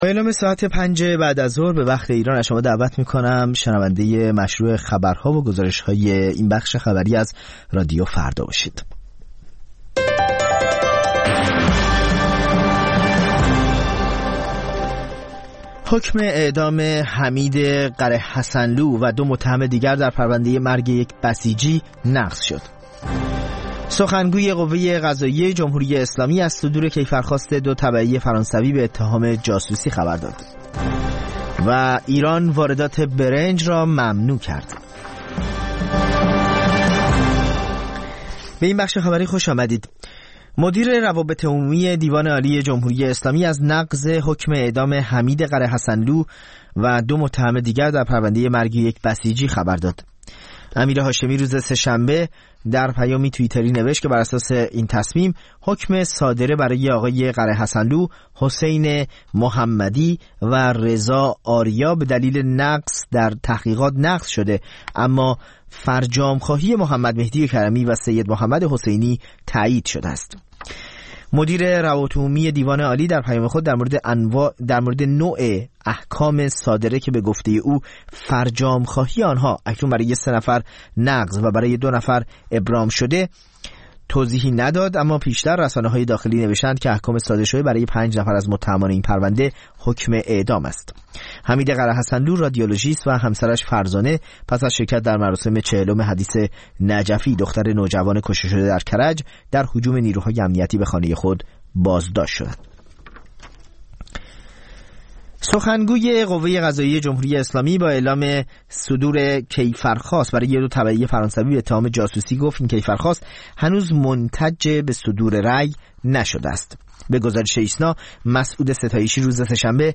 خبرها و گزارش‌ها ۱۷:۰۰